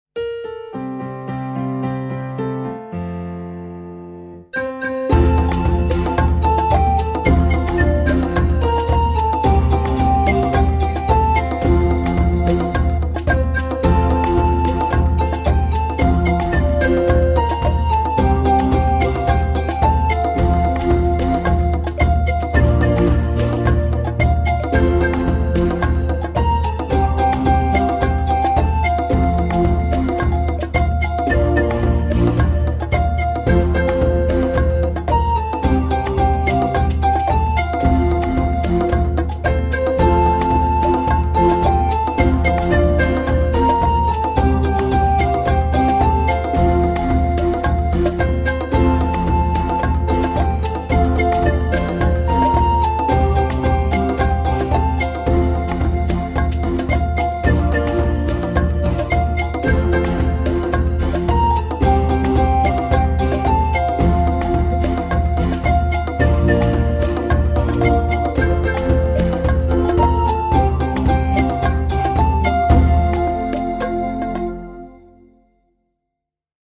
Kada budeš spreman-na, pjesmu možeš pjevati i uz matricu pjesme Siyahamba (instrumentalnu izvedbu).